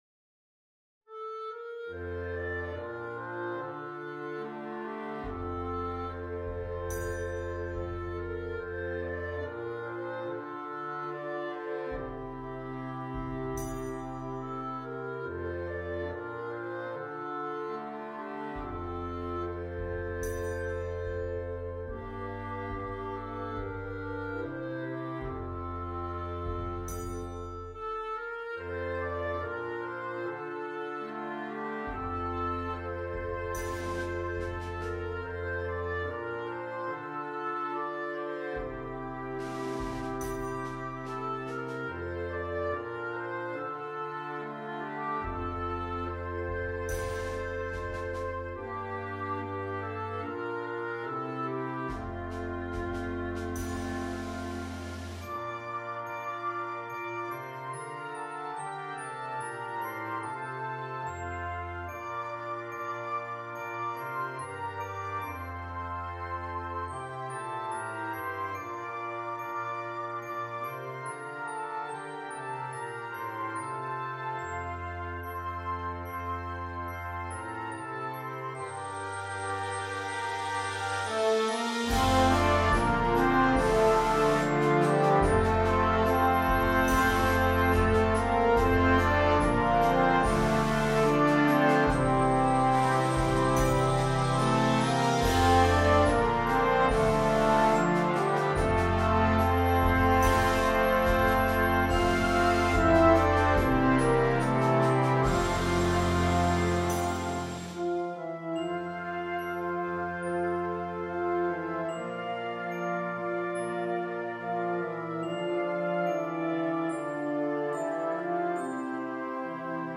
Great for young middle school bands.